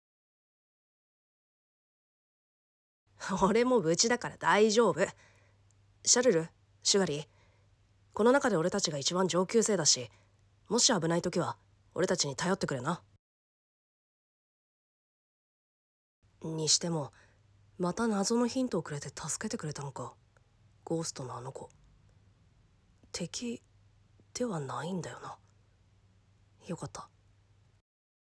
声劇①🌀